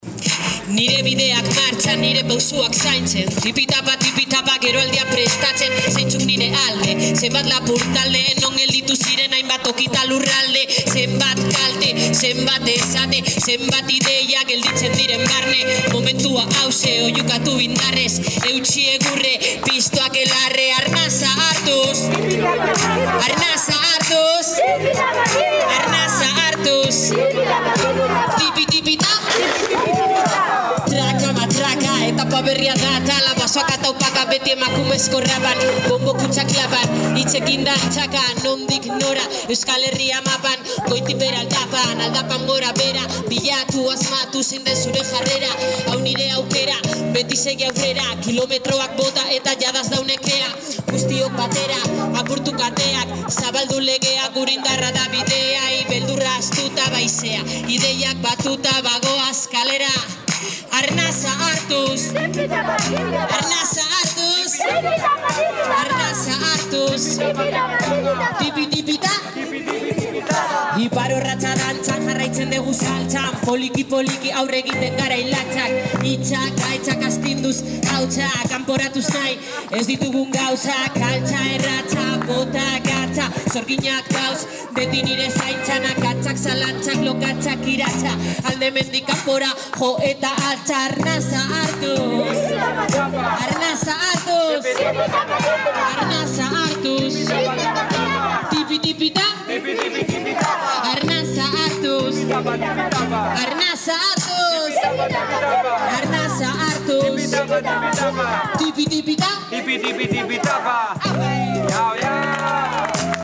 el rap vasco
Escuchemos las diferentes intervenciones musicales sobre la descolonización del pensamiento y la palabra: